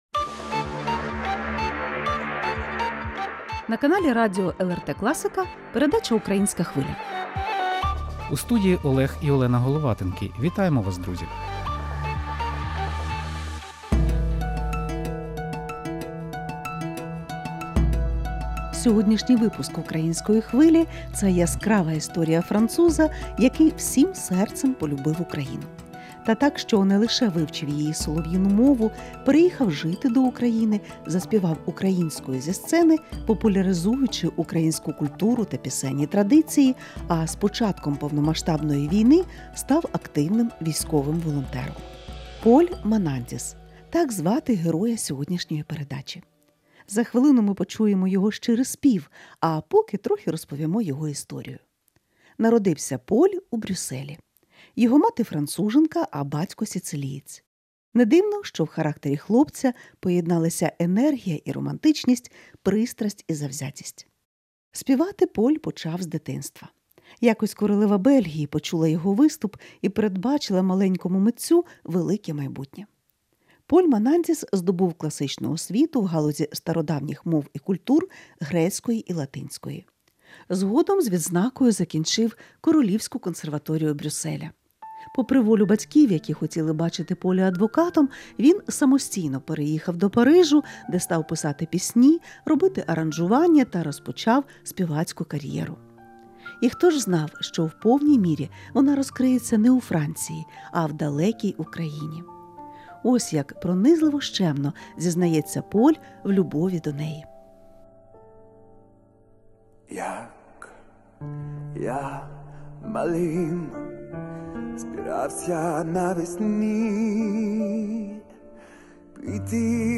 Цей музичний випуск передачі “Українська Хвиля” - яскрава історія француза, народженого в Бельгії, який всім серцем полюбив Україну.
У передачі звучать пісні в його виконанні, цитати митця та розповідь про життя і творчість.